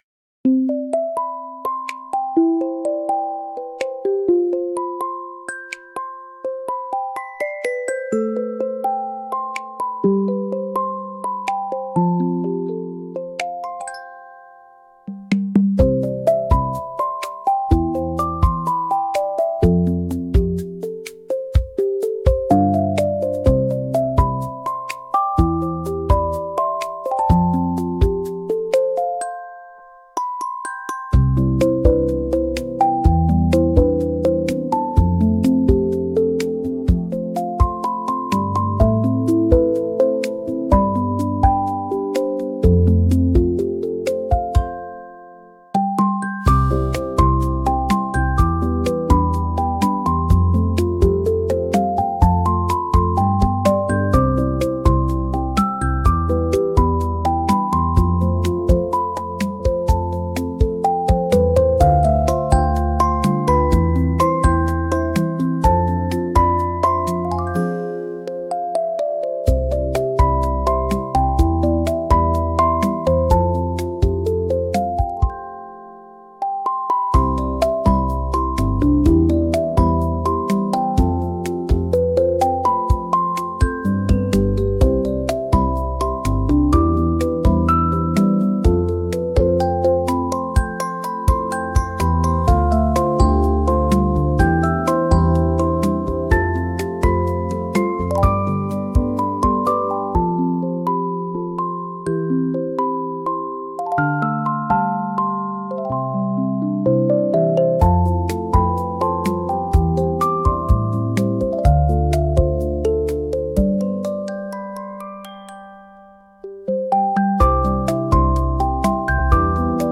ゆめかわいいフリーBGM🧸🎧🫧
とろける音色～リラックスタイムに